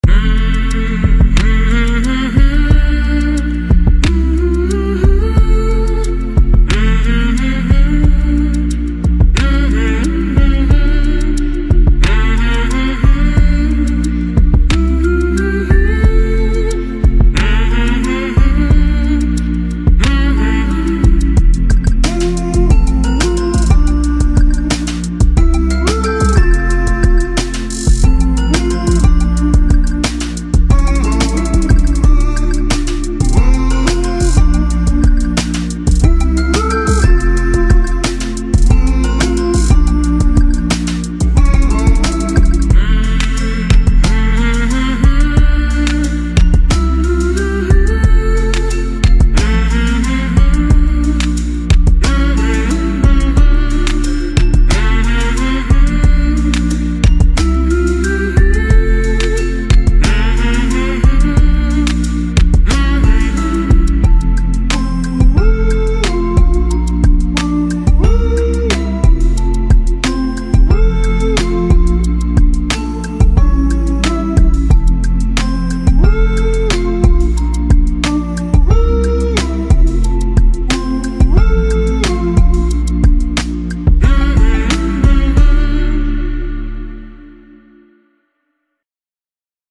мелодичные
без слов
нежные